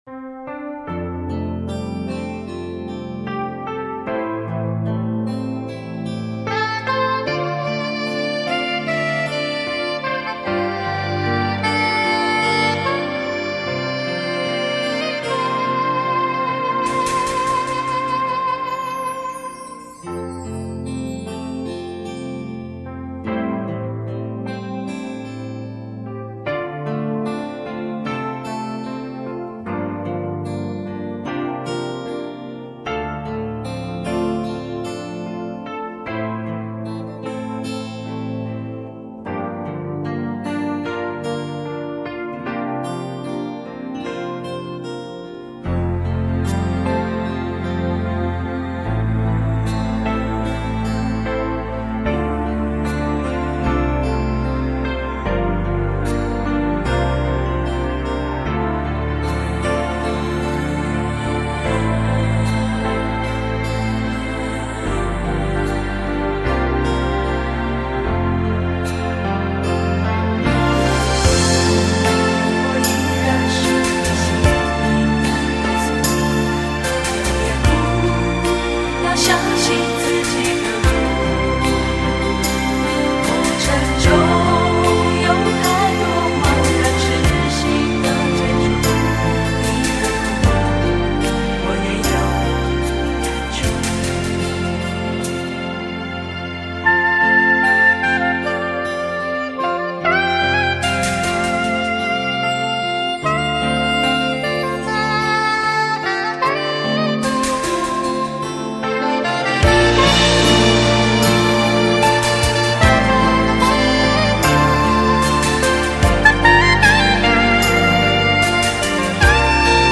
F调伴奏